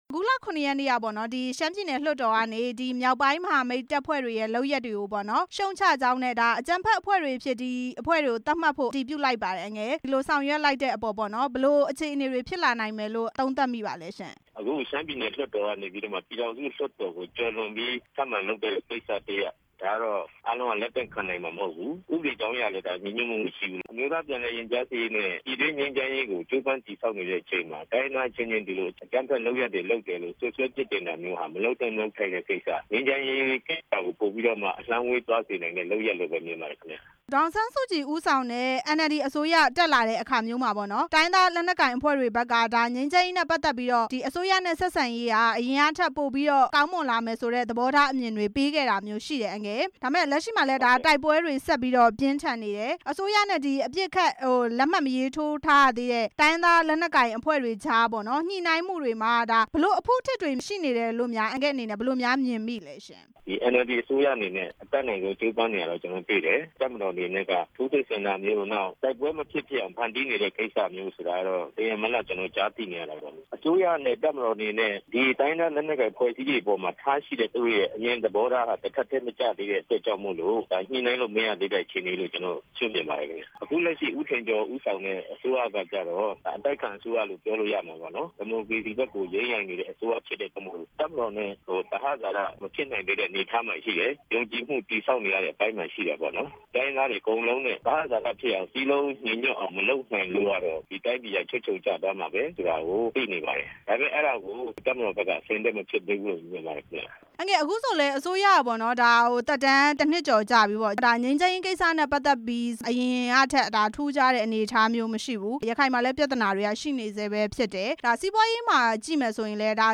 မြောက်ပိုင်းမဟာမိတ်တပ်ဖွဲ့တွေကို အကြမ်းဖက်အဖွဲ့တွေအဖြစ် သတ်မှတ်ကြောင်း မေးမြန်းချက်